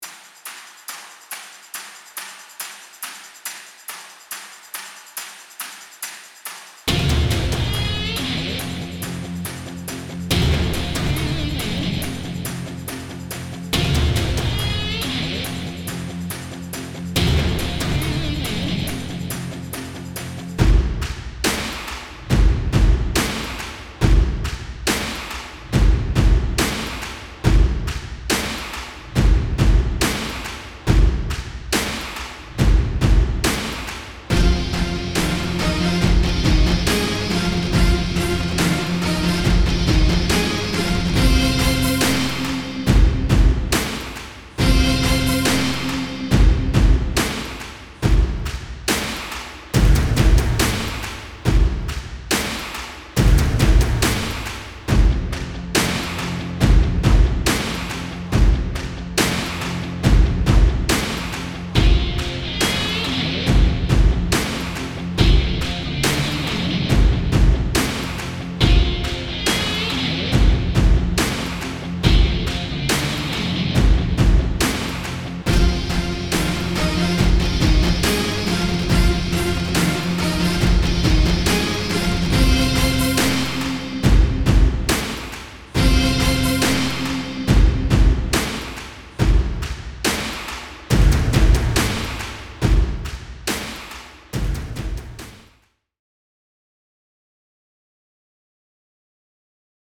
Entry BGM